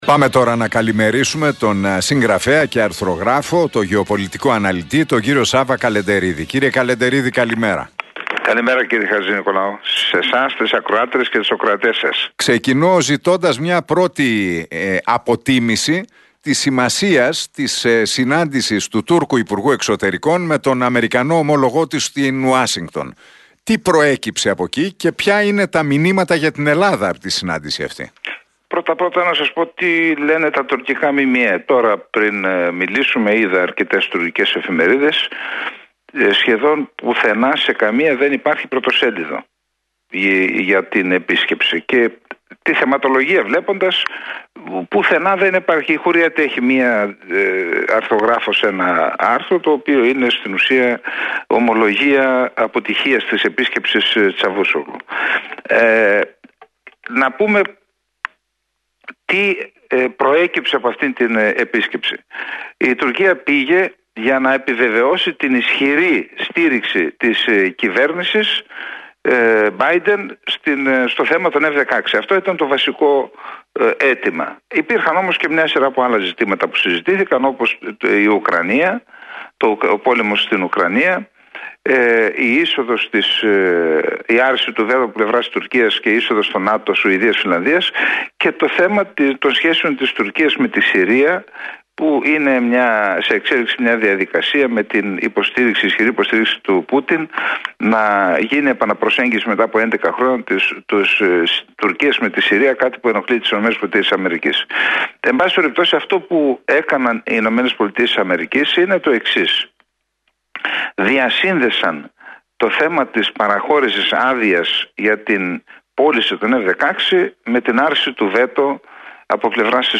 Η ανάλυση από τον γεωπολιτικό αναλυτή, συγγραφέα και αρθρογράφο